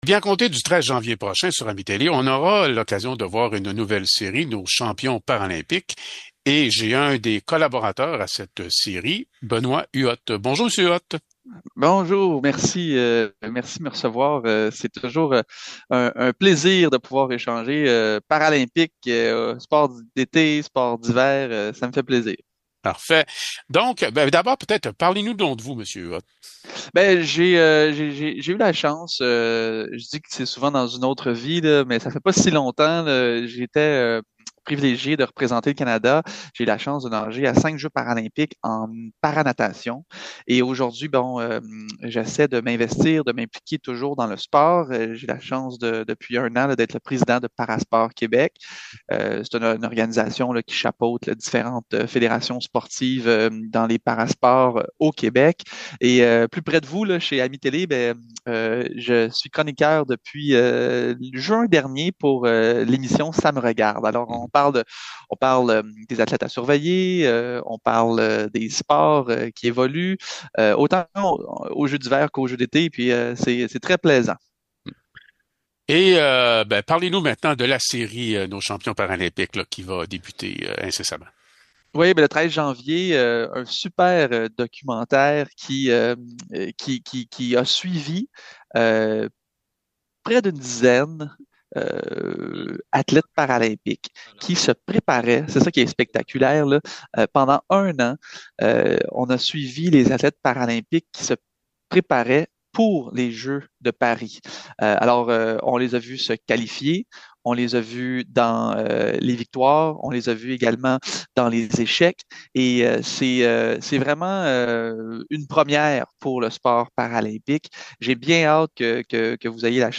Nous continuons nos entrevues avec les artisans d’AMI-Télé. On débute l’année avec Benoit Huot, collaborateur de la nouvelle série Nos champions paralympiques qui sera en onde le 13 janvier prochain.